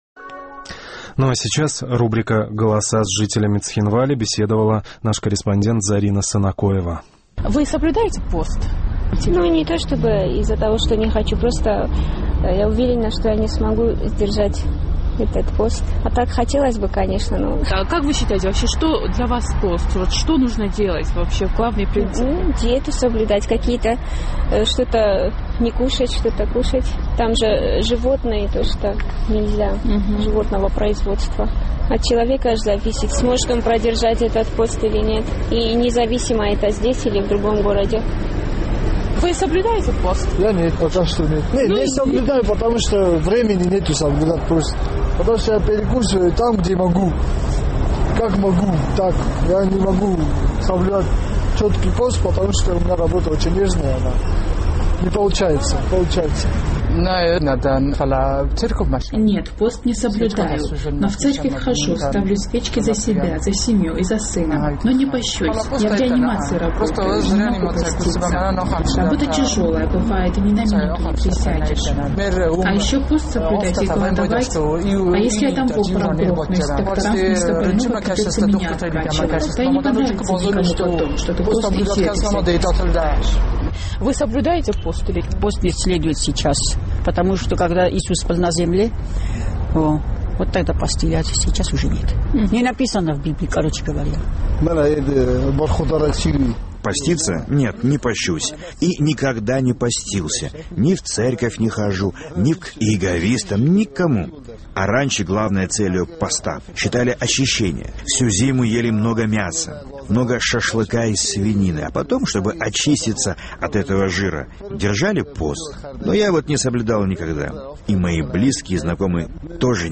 В ходе опроса жителей Цхинвала, наш корреспондент выяснила, что большинство населения не соблюдает пост. Некоторые в силу загруженности рабочего графика, а кто-то в силу убеждений.